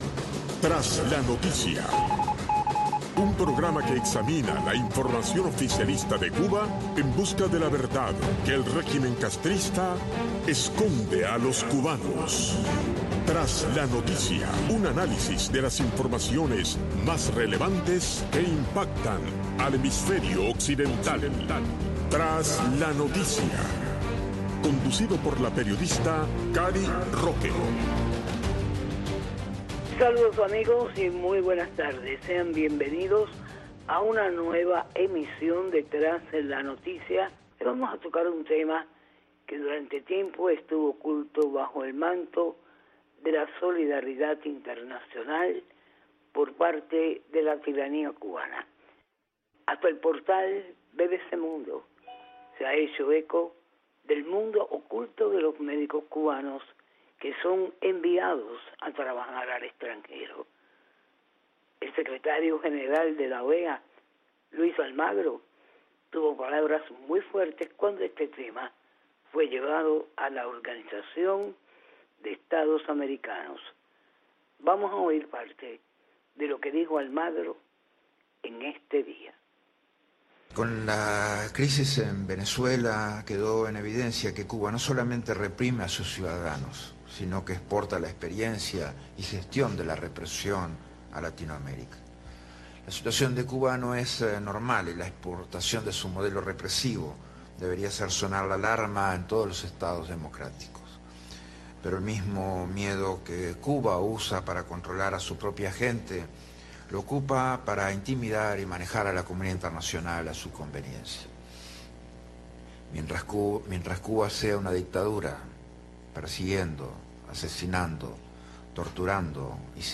conversa con dos destacados doctores